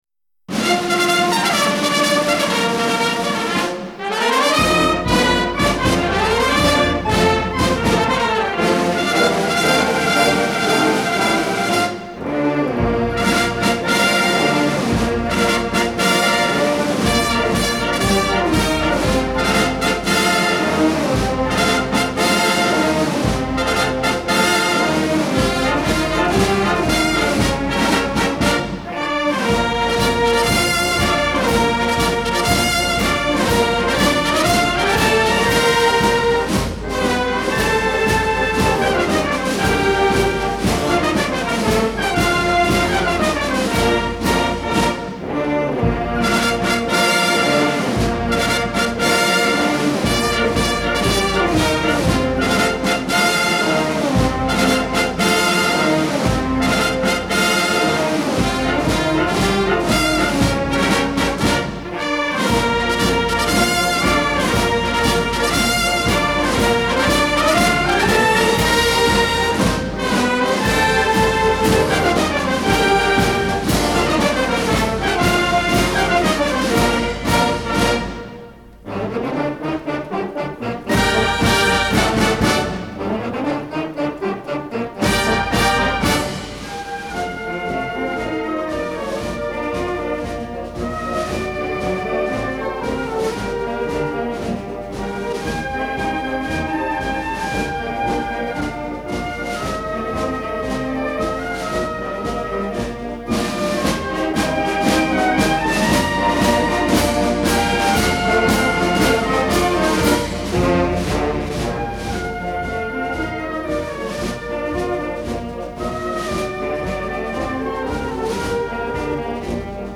MP3:  Marcha San Lorenzo (instrumental)
Los autores lograron un relato sencillo y expresivo de los hechos, con descripción poética y realista, en la que se amalgaman letra y música con la maestría que hizo de la Marcha San Lorenzo una de las canciones patrias más conocidas y cantadas por los argentinos y reconocida internacionalmente como una de las mejores cinco marchas militares del mundo.
Marcha de San Lorenzo - Instrumental (Sinfonica del Ejercito).mp3